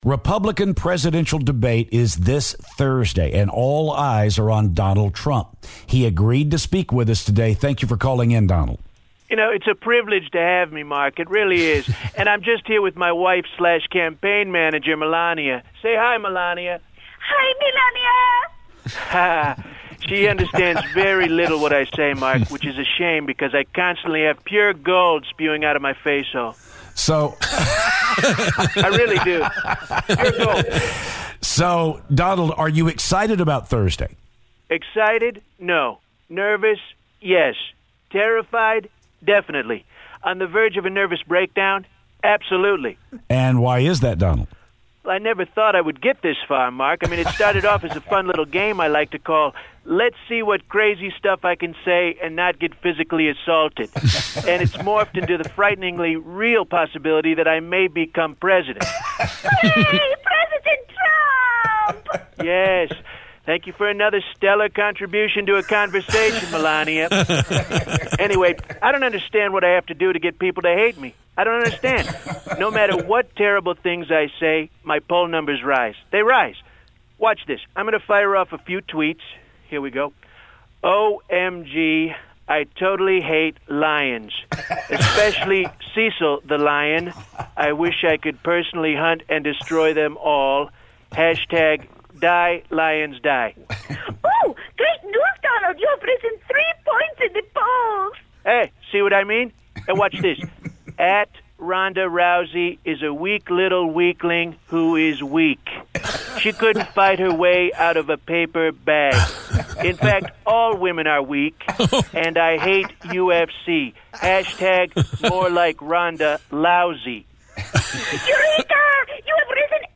Donald Trump calls the show to talk about the debate on Thursday.